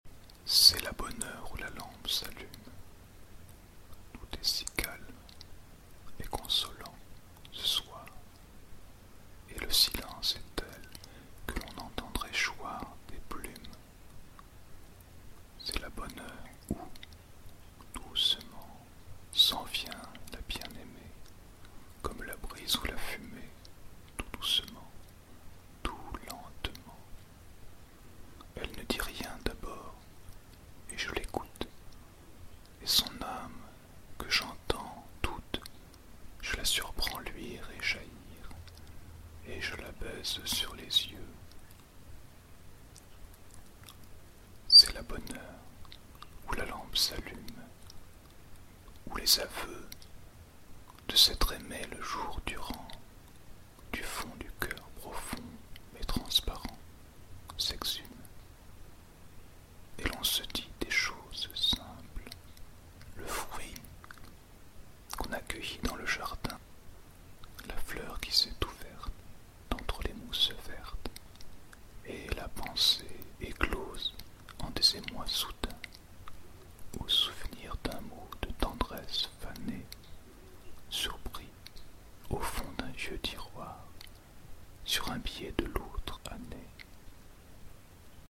Extrait du poème
En Slow&LowReading